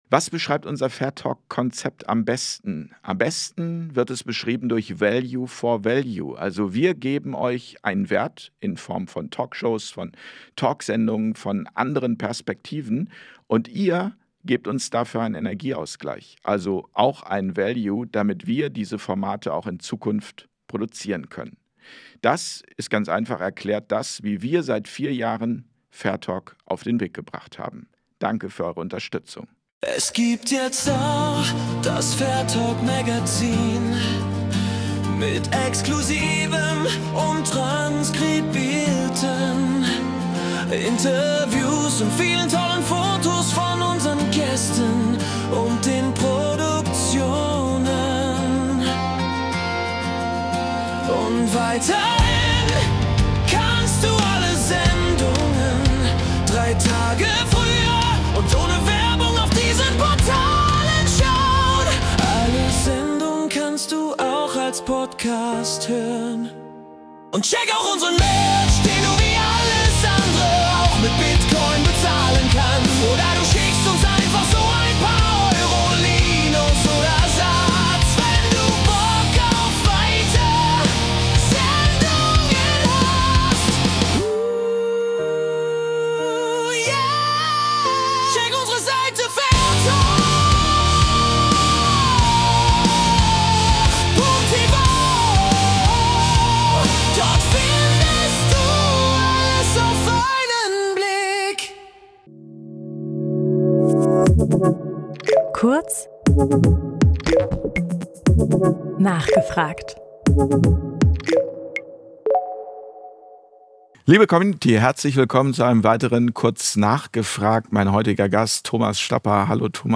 Das Interview Format